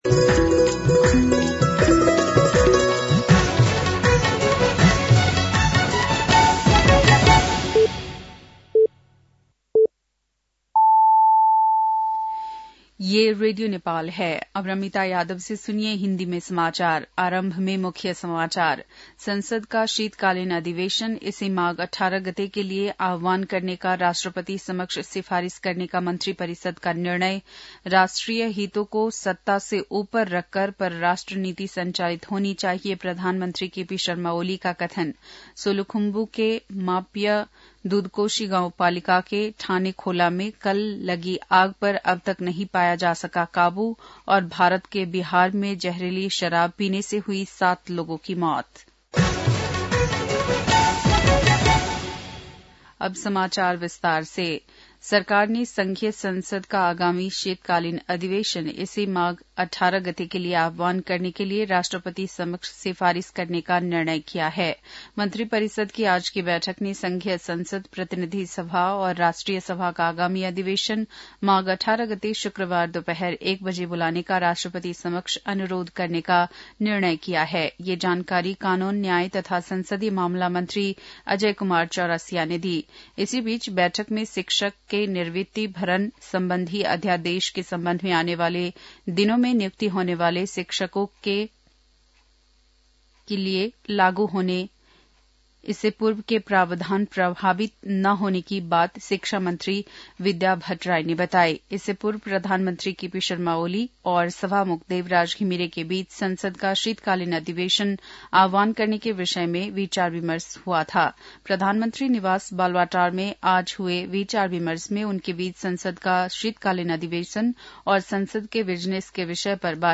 बेलुकी १० बजेको हिन्दी समाचार : ८ माघ , २०८१